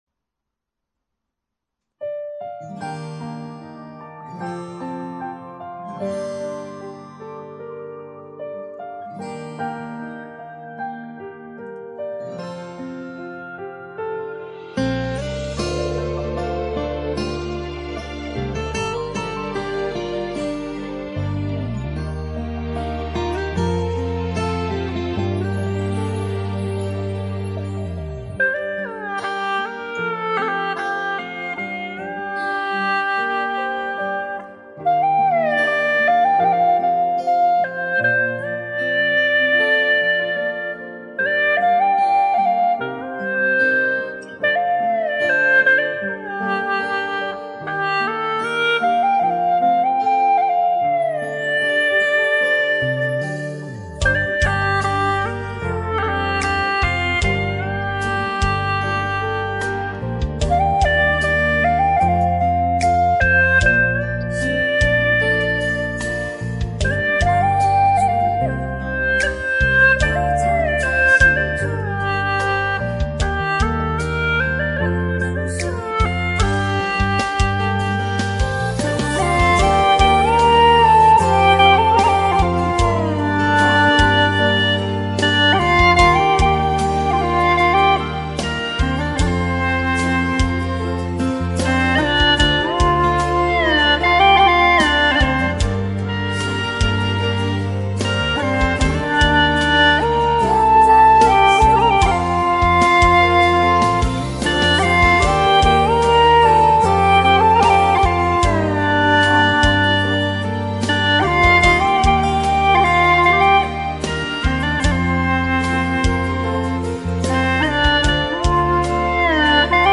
调式 : 降B 曲类 : 流行
【降B调 做5 + 降E调 做1】 我要评论